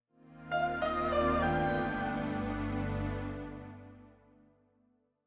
Longhorn 2000 - Log Off.wav